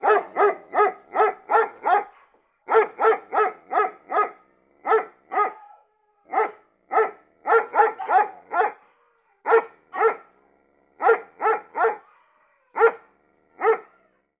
Лай обычной собаки